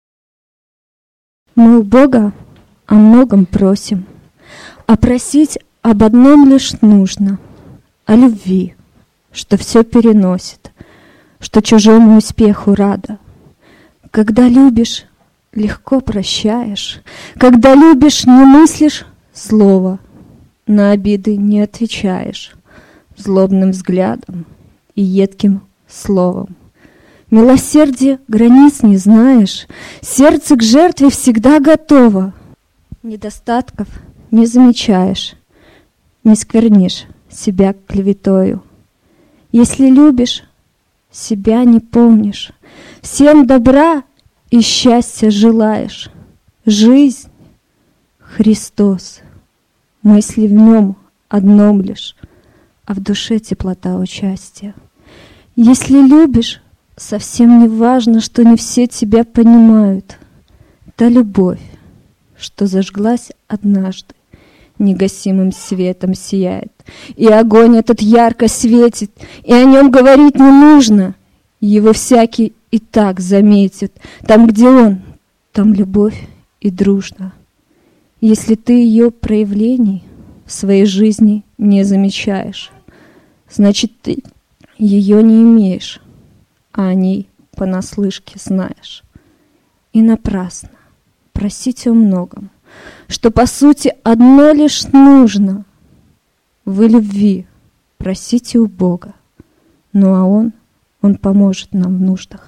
Мы у Бога о многом просим. Стихотворение